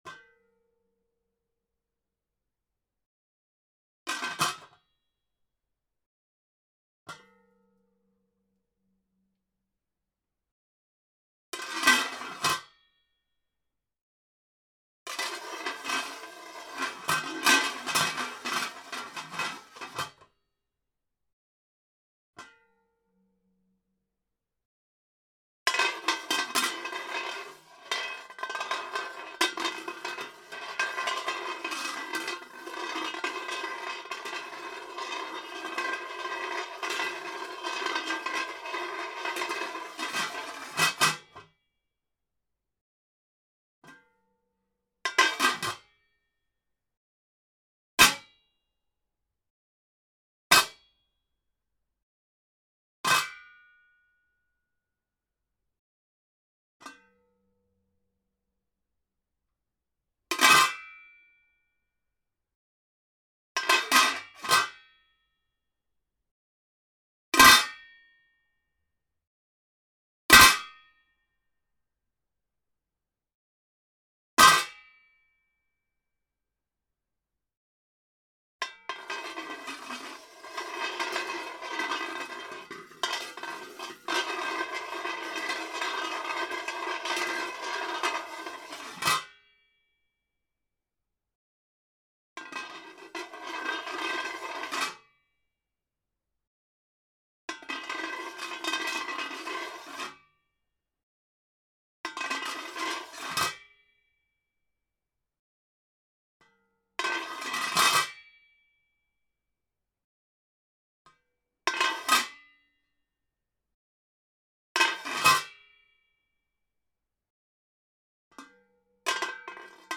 Iron pot
bell clang clank close ding drop drumstick hand sound effect free sound royalty free Music